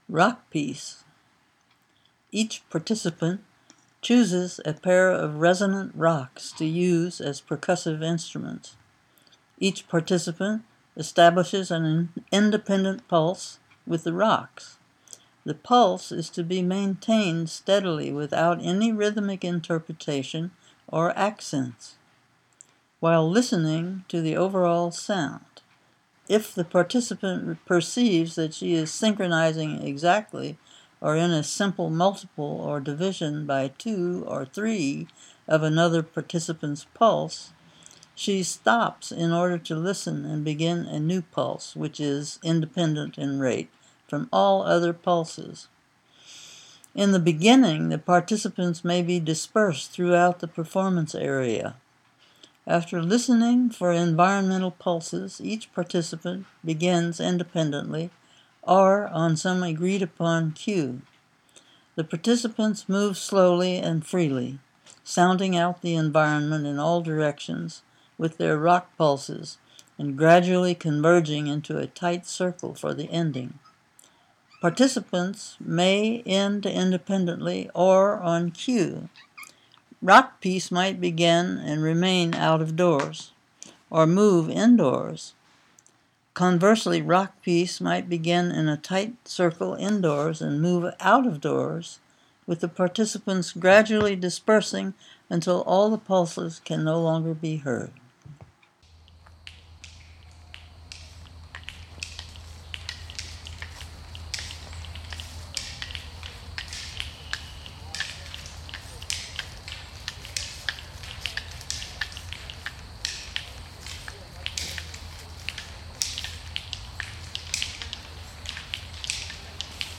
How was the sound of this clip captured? Olana State Historic Site